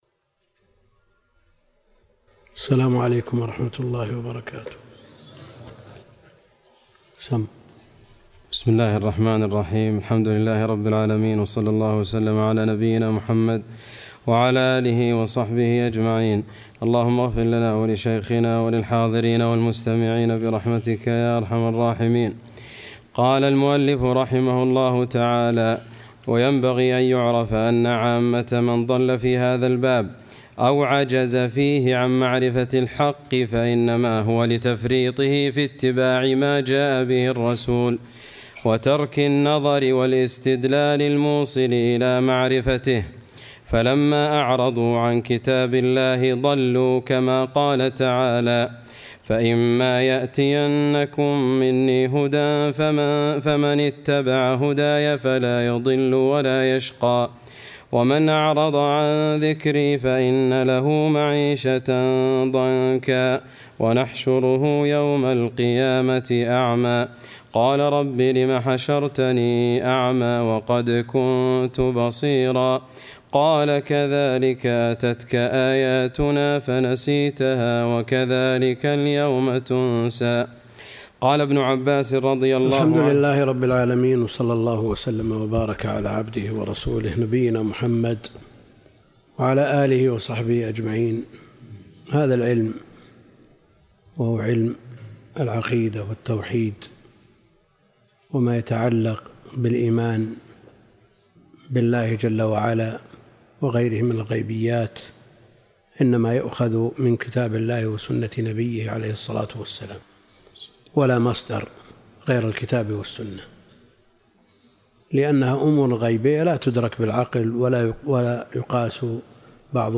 الدرس (2) شرح العقيدة الطحاوية - الدكتور عبد الكريم الخضير